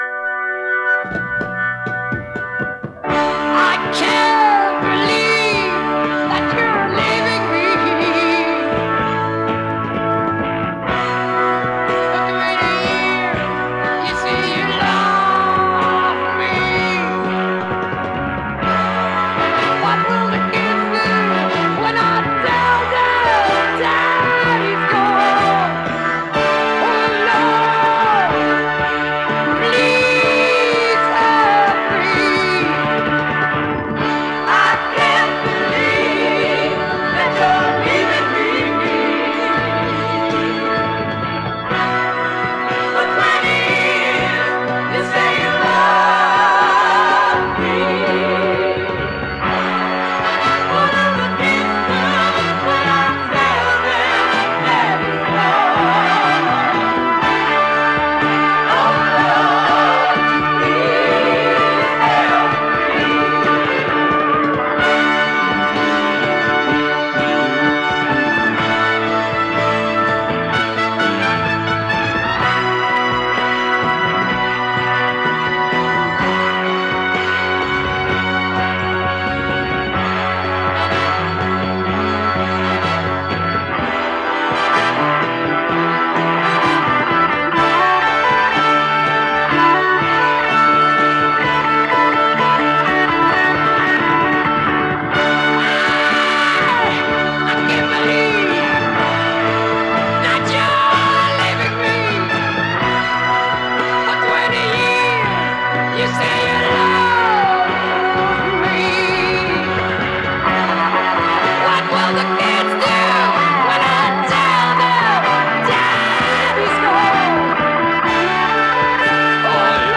Recorded: Olympic Sound Studio in Barnes / London, England